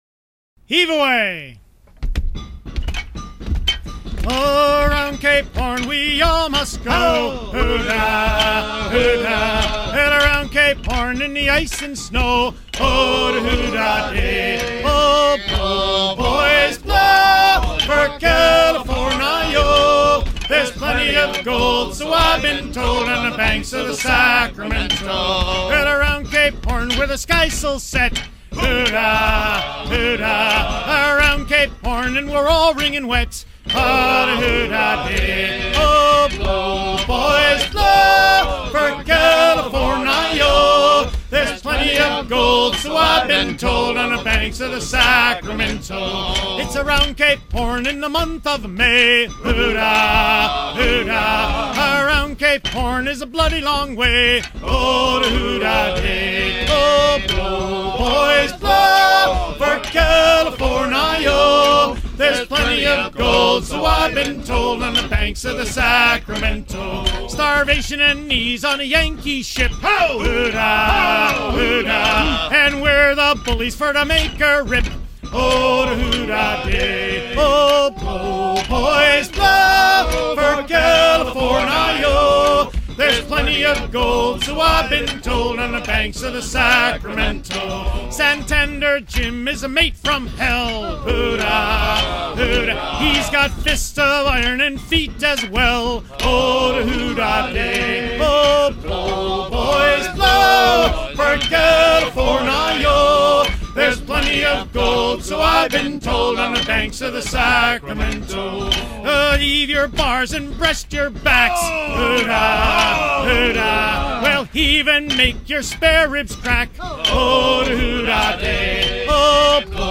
Chantey américain interprété en virant le cabestan du trois-mâts Joseph Conrad, à quai au port-musée de Mystic Seaport
Pièce musicale éditée